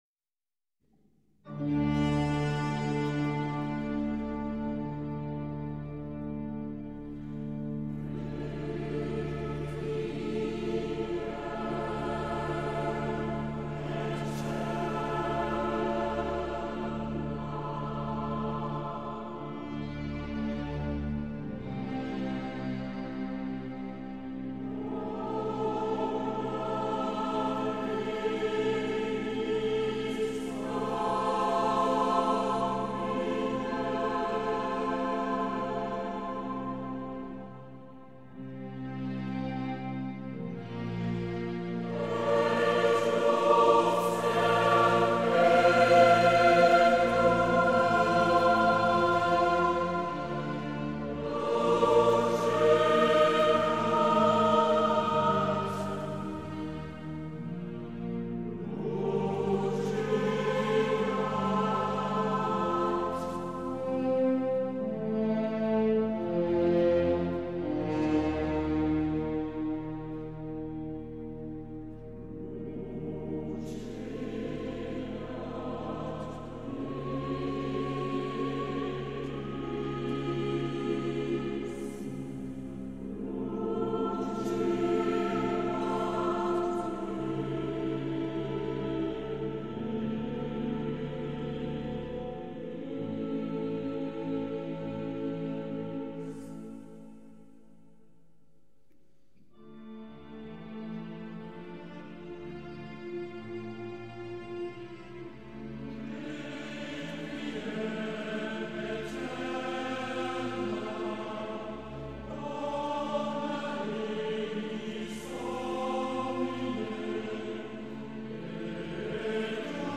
St. Jodokus - Immenstaad - 31. März 2012